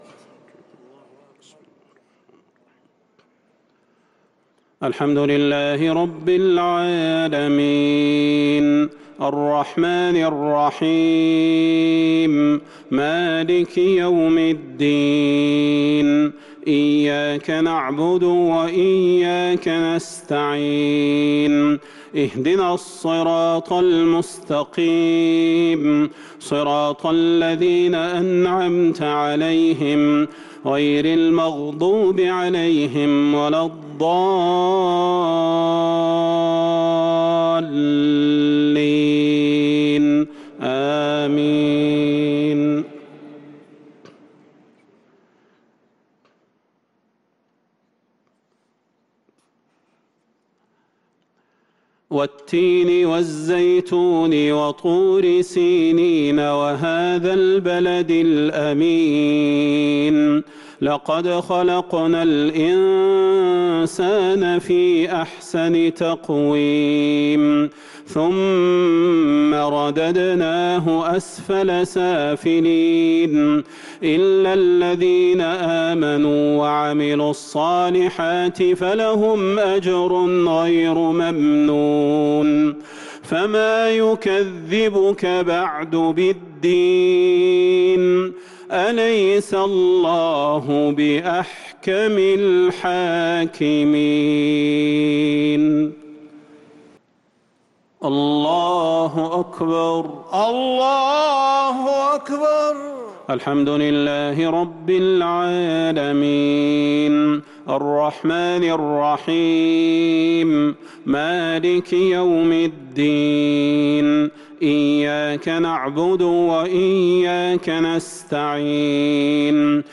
صلاة العشاء للقارئ صلاح البدير 6 رمضان 1443 هـ
تِلَاوَات الْحَرَمَيْن .